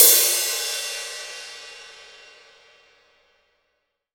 Index of /90_sSampleCDs/AKAI S-Series CD-ROM Sound Library VOL-3/16-17 CRASH